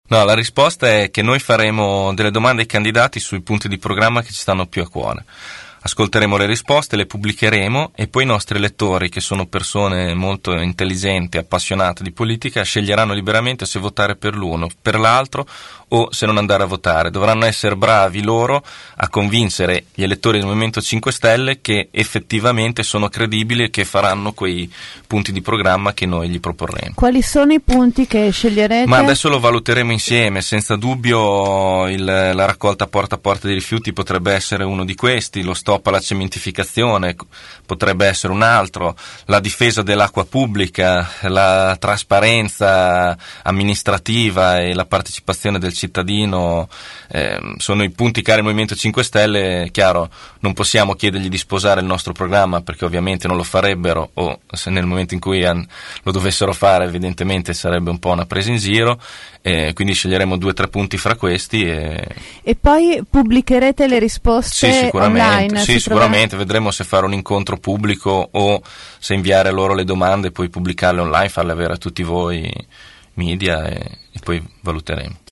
ospite dei nostri studi